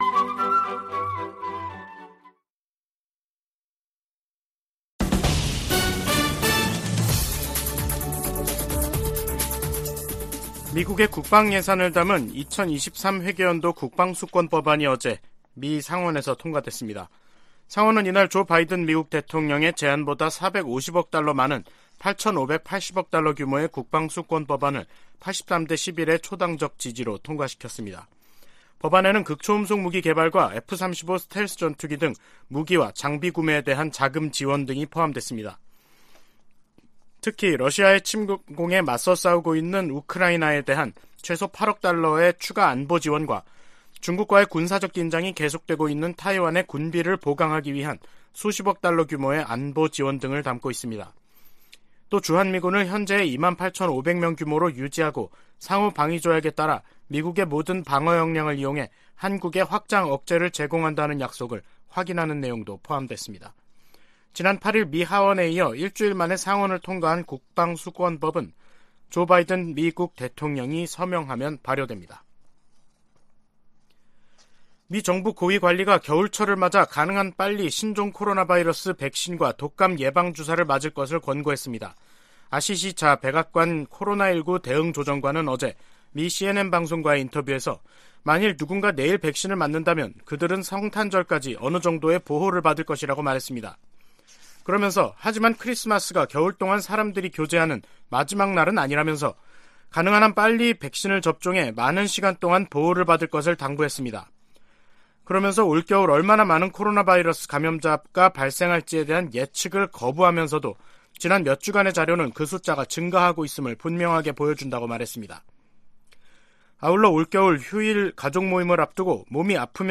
VOA 한국어 간판 뉴스 프로그램 '뉴스 투데이', 2022년 12월 15일 2부 방송입니다. 북한이 대륙간탄도미사일로 보이는 고출력 고체엔진 시험에 성공했다고 주장했습니다.